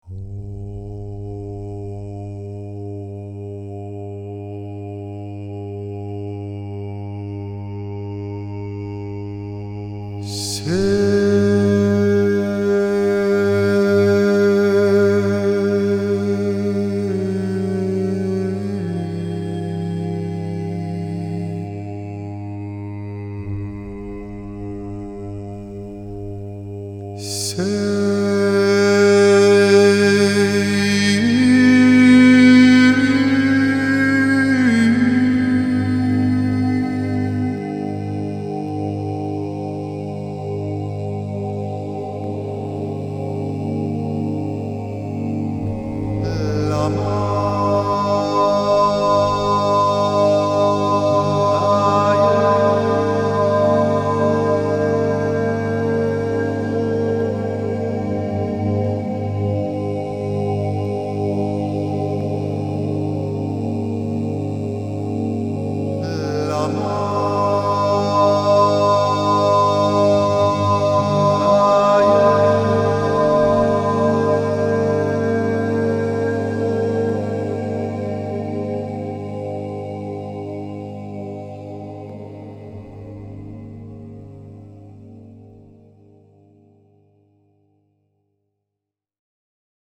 I don't think of any specific words when I sing.